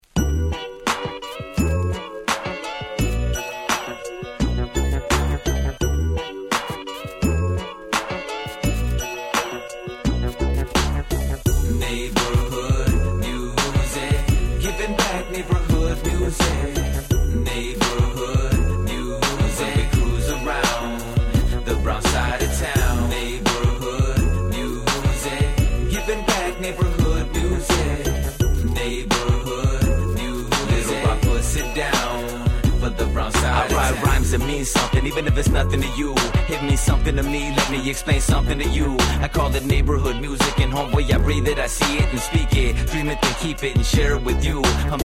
04' Nice チカーノRap !!